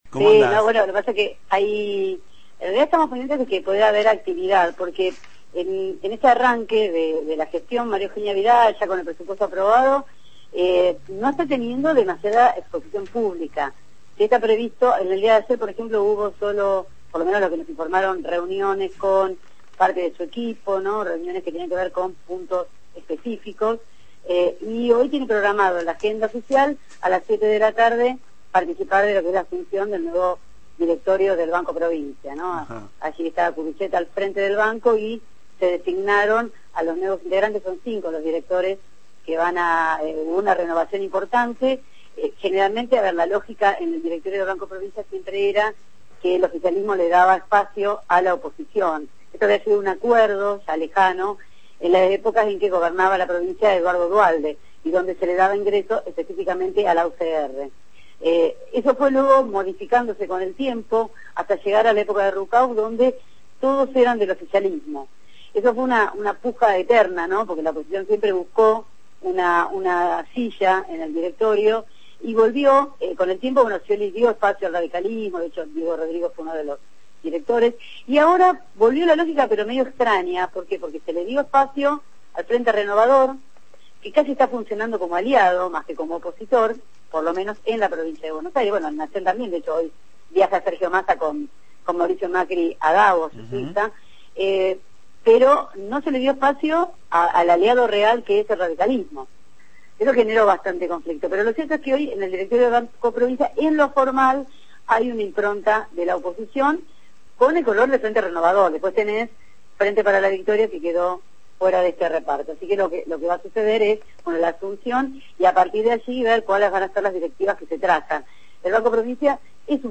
realizó su habitual informe sobre la actualidad política bonaerense. En esta oportunidad se refirió a la asunción de nuevas autoridades en el Banco Provincia.